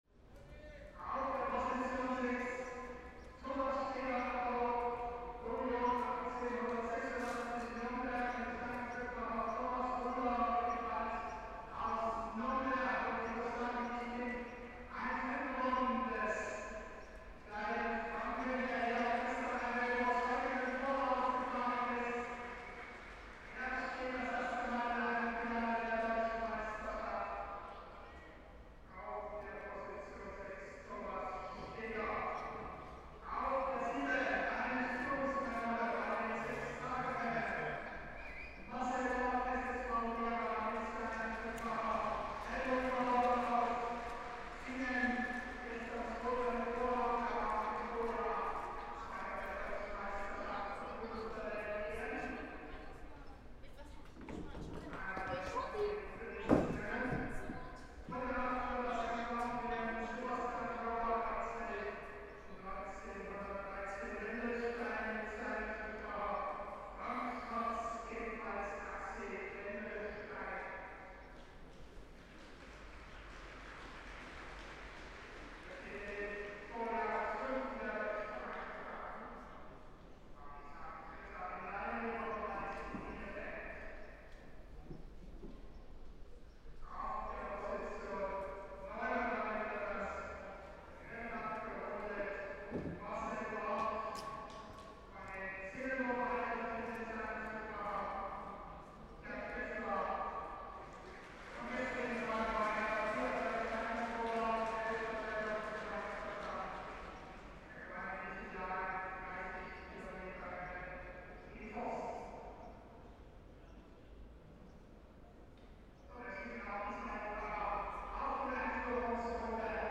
Last weekend, there were the german motor-paced racing championships. At first glance this is quite the opposite of what I’ve described above but I was tempted to find out if I could enjoy the combination of cycling and heavy noise made by the pacemaker’s motorcycles. At the end I spent two afternoons there and made sound recordings of all races and some atmosphere recordings of the location an hour before the first race.
PFR10155, 140809, German motor-paced race championship finals, Radrennbahn, Leipzig, excerpt, mix